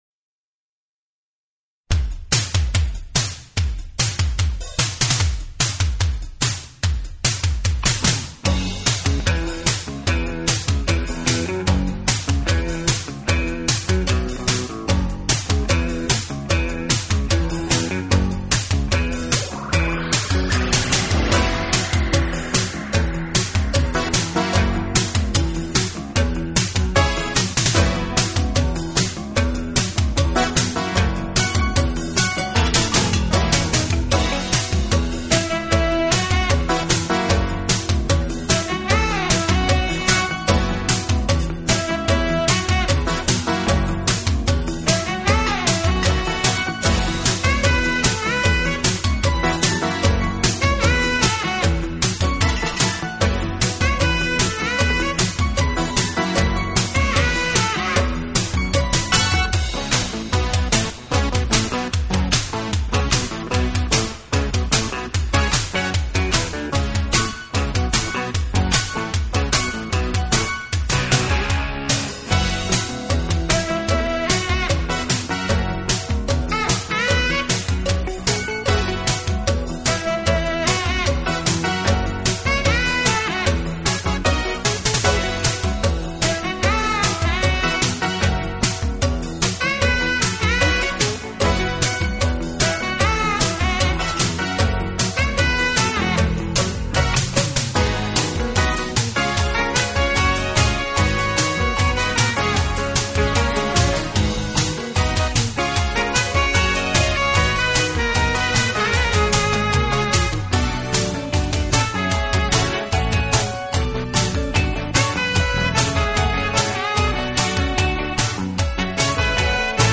【爵士吉他】
风格：Jazz-Pop、Crossover Jazz、Smooth Jazz